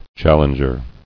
[chal·leng·er]